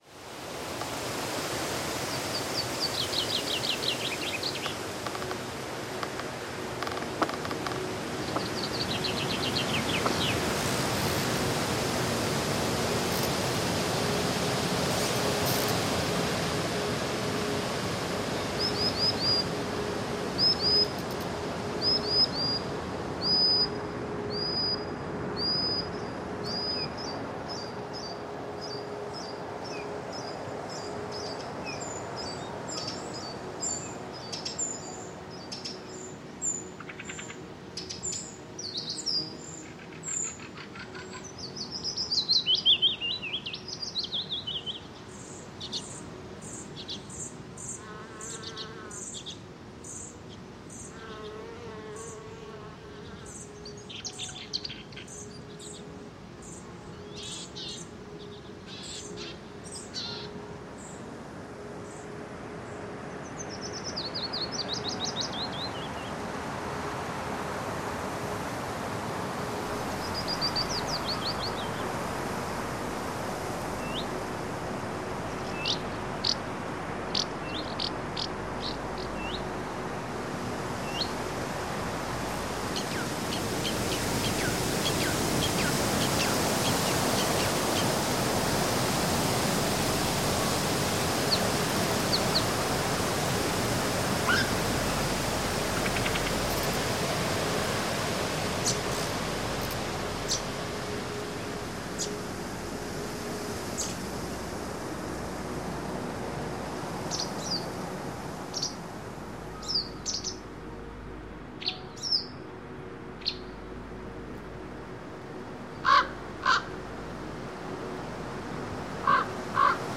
在这里，您可以感受到强劲的风声、奔流的瀑布声以及宁静的森林氛围。音效将带您穿越挪威的野生森林，沉浸在远离尘嚣的自然声音中。
声道数：立体声+四声道